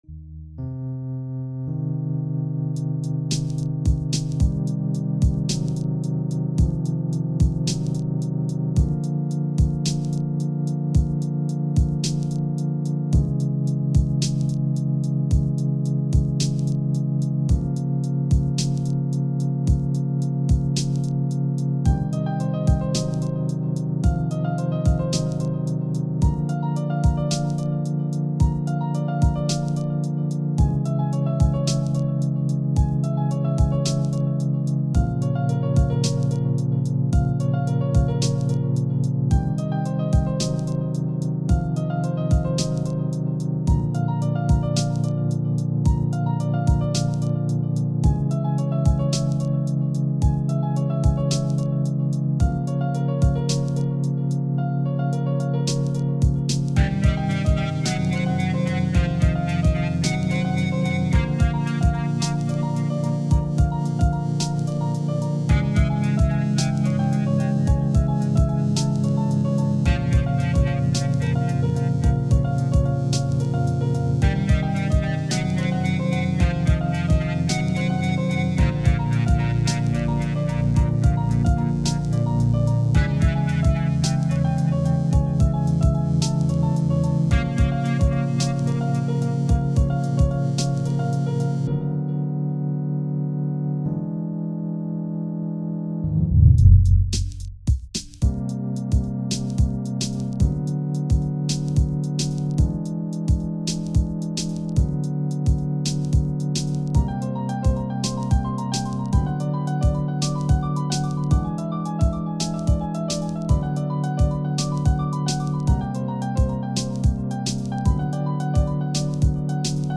сыро и плоско, как коврик в ванной.
ещё и рвано, как будто коврик пытались засунуть в бельевую корзину.